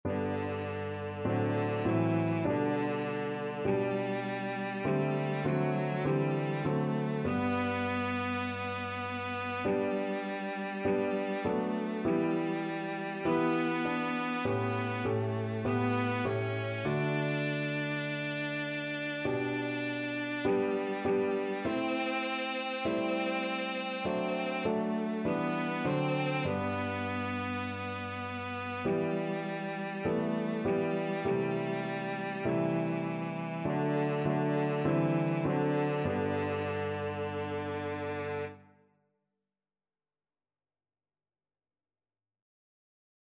Christian Christian Cello Sheet Music Savior, Again to Your Dear Name We Raise
Cello
G major (Sounding Pitch) (View more G major Music for Cello )
2/2 (View more 2/2 Music)
D4-D5
Classical (View more Classical Cello Music)